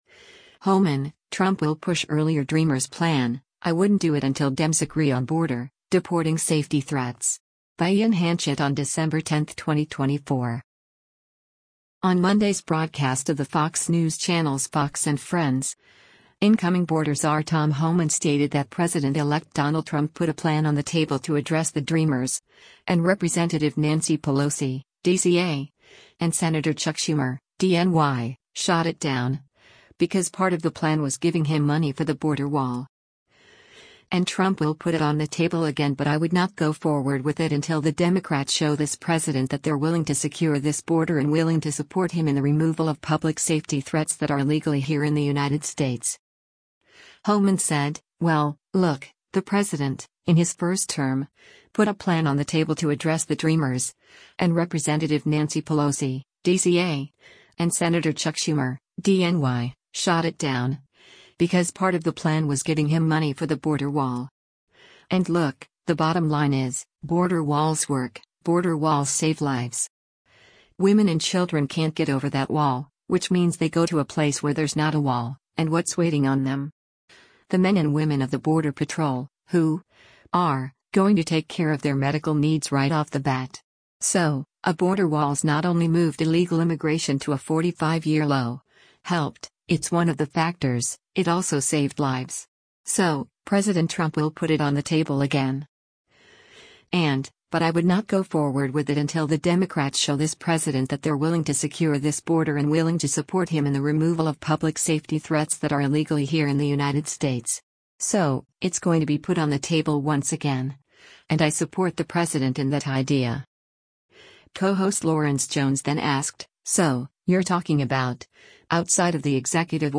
On Monday’s broadcast of the Fox News Channel’s “Fox & Friends,” incoming Border Czar Tom Homan stated that President-Elect Donald Trump “put a plan on the table to address the DREAMers, and Rep. Nancy Pelosi (D-CA) and Sen. Chuck Schumer (D-NY) shot it down, because part of the plan was giving him money for the border wall.” And Trump “will put it on the table again” “but I would not go forward with it until the Democrats show this president that they’re willing to secure this border and willing to support him in the removal of public safety threats that are illegally here in the United States.”